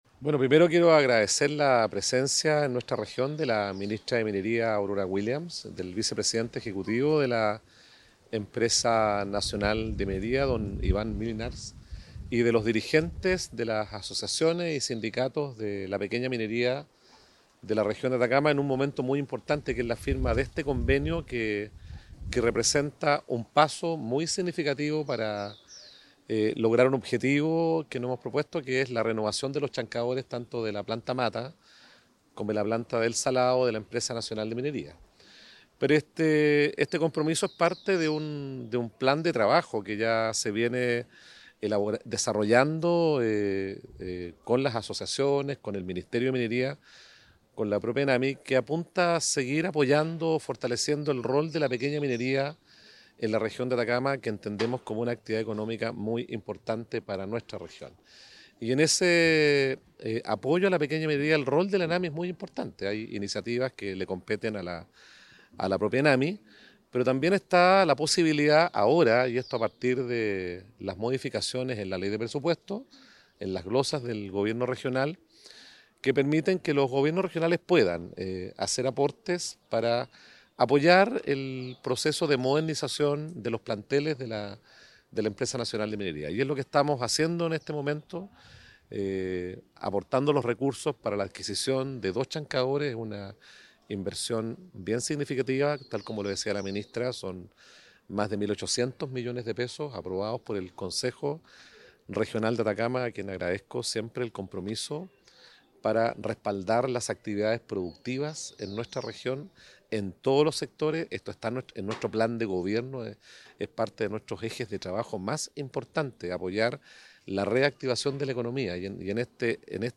El Gobernador Regional, Miguel Vargas Correa, expresó la importancia de este convenio, resaltando que es “un paso muy significativo para la renovación de los chancadores en las plantas de ENAMI, apoyando directamente a la pequeña minería, una actividad fundamental para la economía de nuestra región.”
GOBERNADOR-DE-ATACAMA-1.mp3